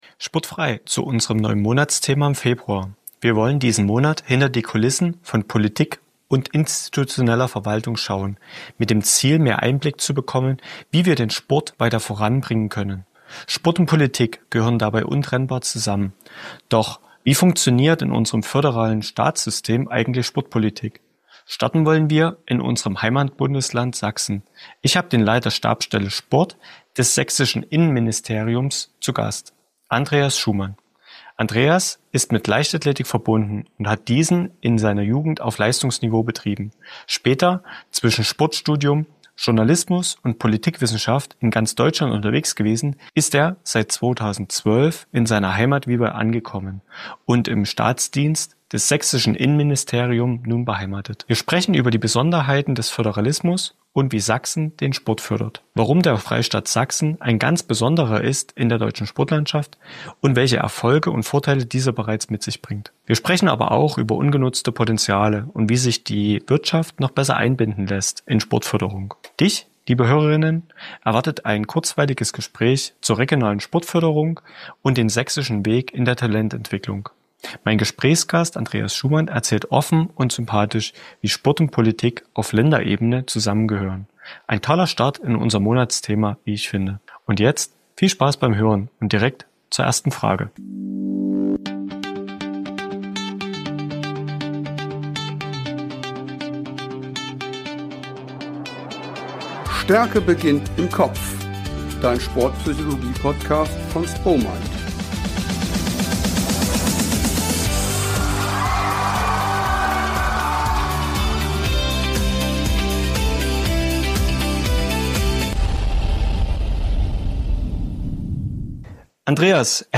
Unser heutiger Gast